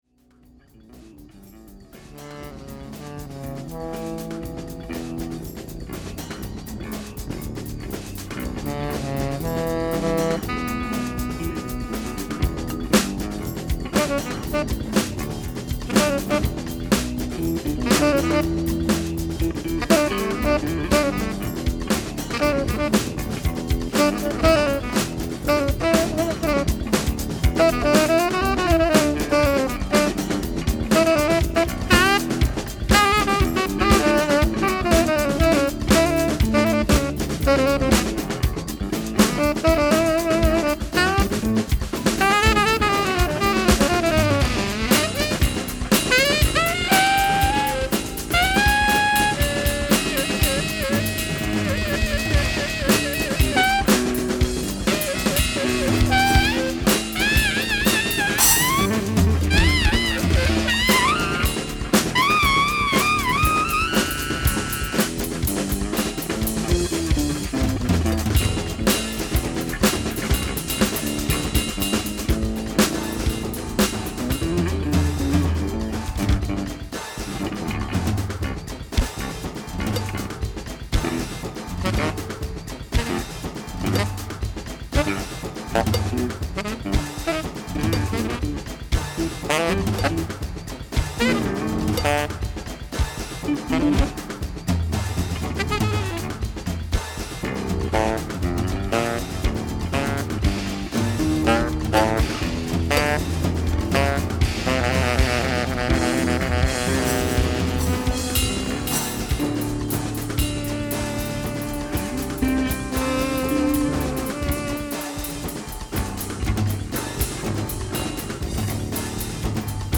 sax and flute
drums and keyboards